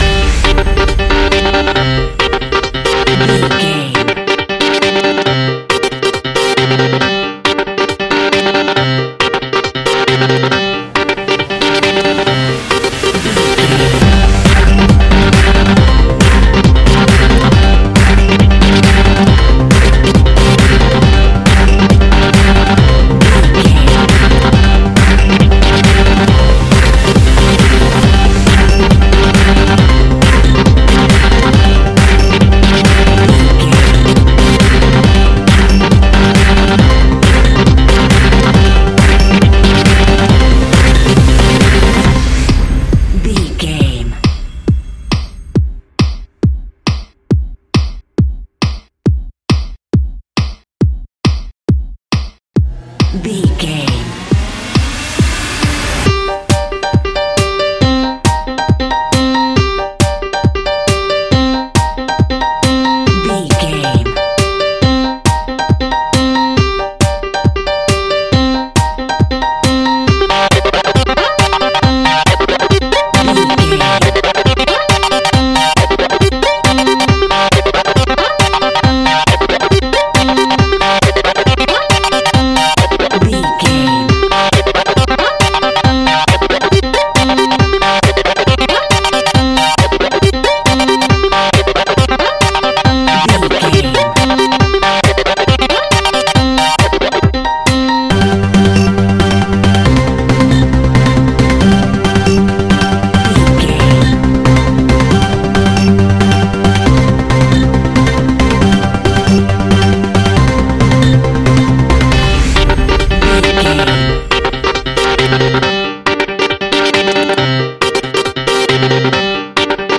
royalty free music
Aeolian/Minor
Fast
futuristic
energetic
uplifting
hypnotic
drum machine
piano
synthesiser
uptempo
instrumentals
synth leads
synth bass